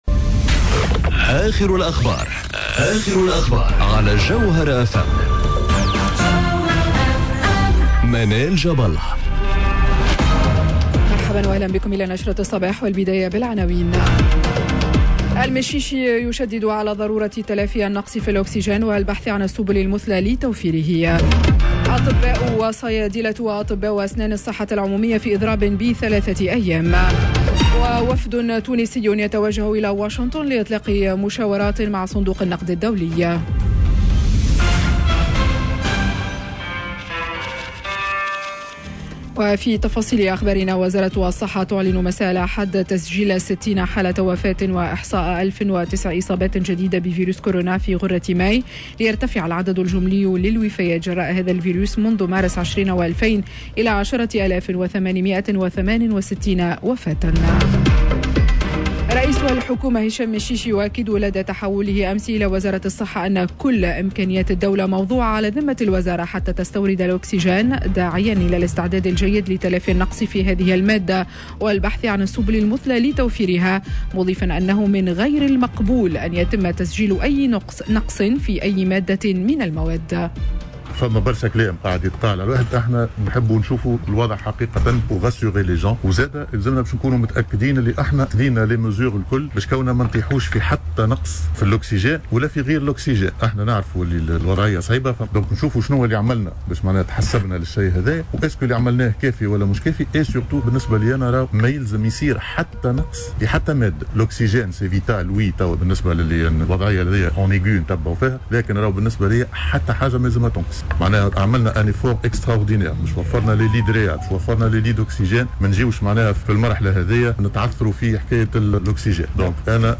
نشرة أخبار السابعة صباحا ليوم الإثنين 03 ماي 2021